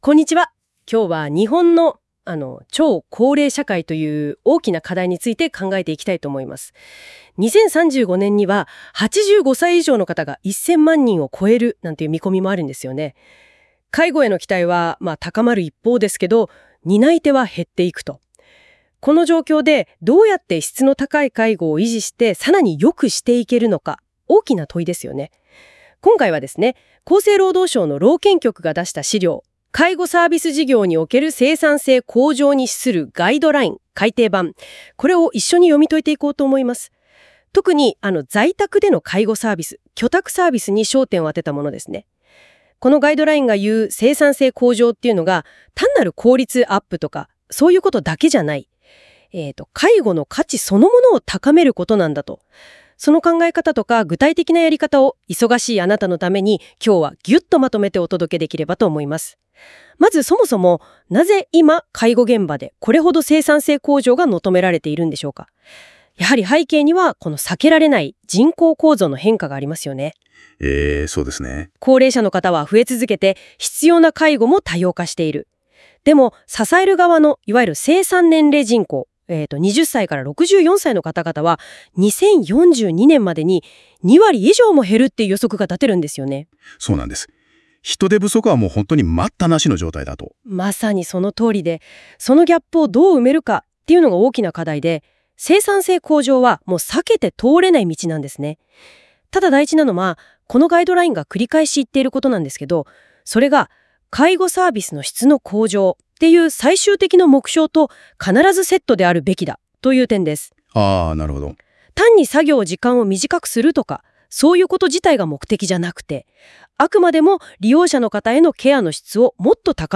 NotebookLMで音声化したので、興味ある方は聞いてみてください。